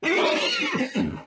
animalia_horse_hurt.ogg